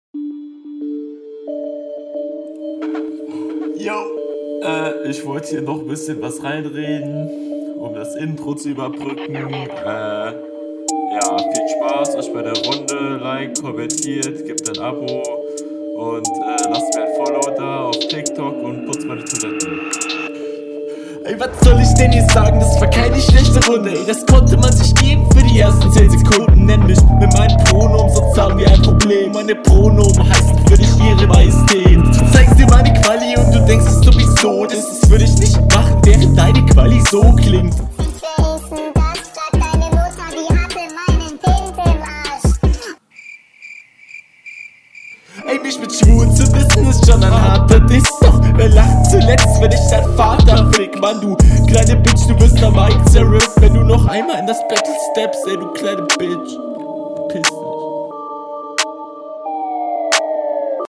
Alter, der Einspieler ist ja noch schlimmer als der in der HR2, gut gekontert.